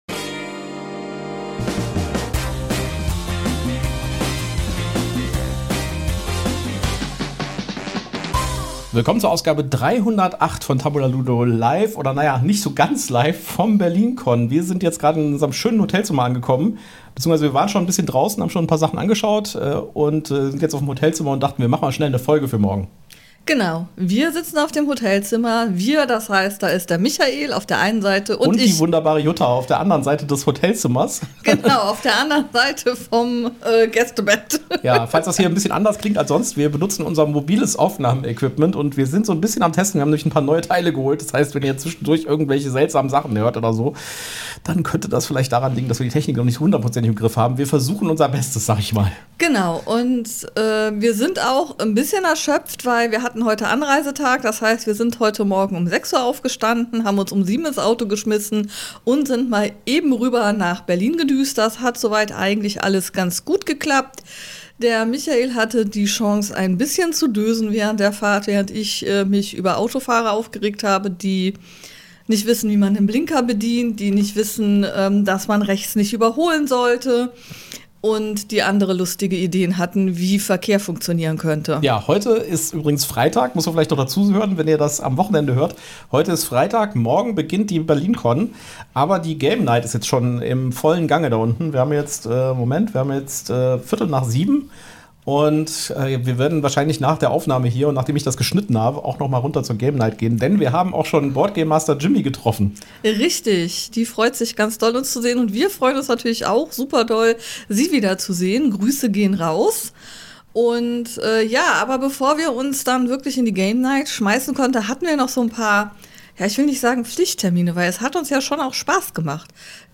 Special: Angespielt auf dem BerlinCon 2025 ~ Tabulaludo Podcast